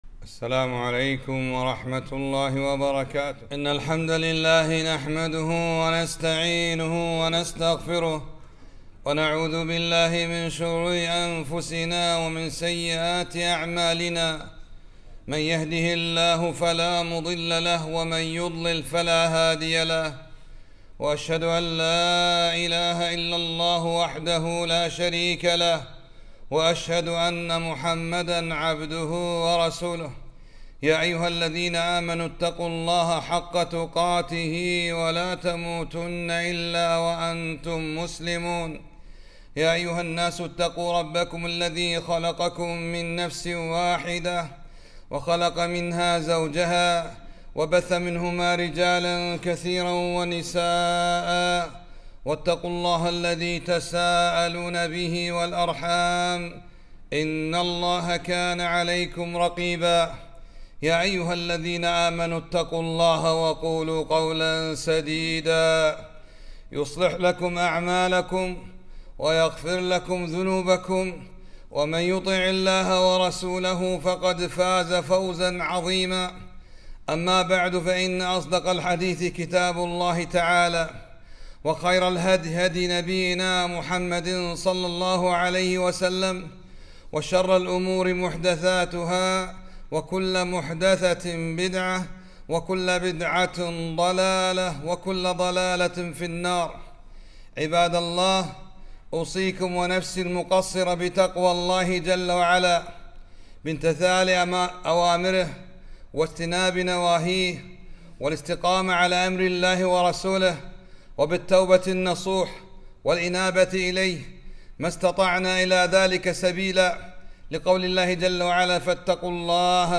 خطبة -